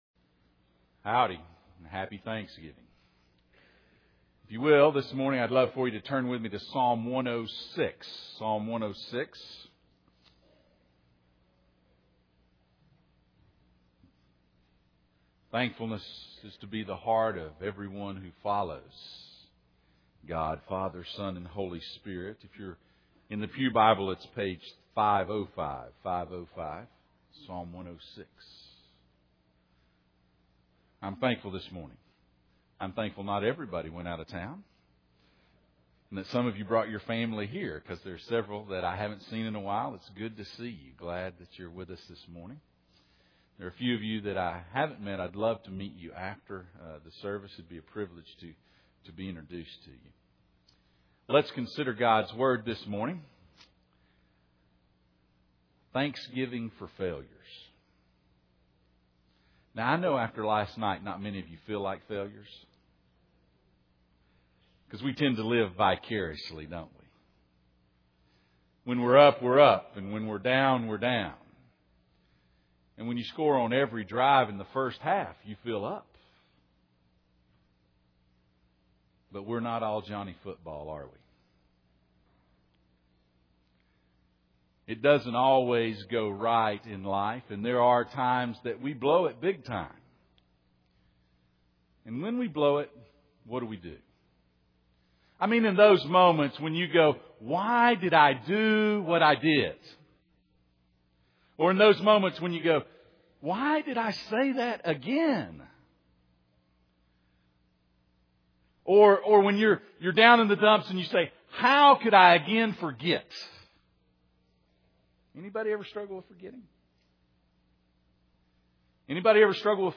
Psalm 106:1-5 Service Type: Sunday Morning « Applying the Cross Practicing Resurrection